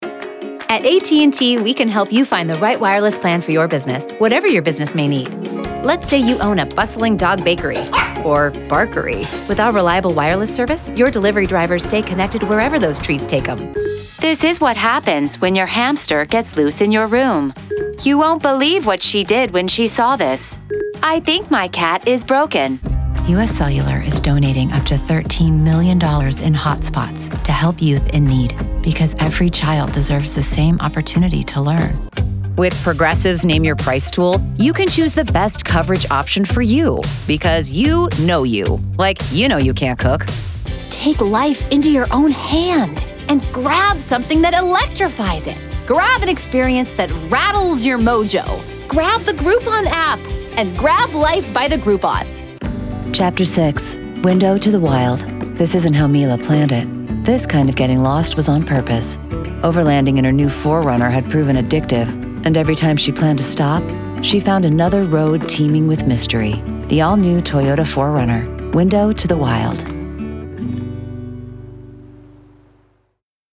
Female Voice Over Talent, Artists & Actors
Yng Adult (18-29) | Adult (30-50)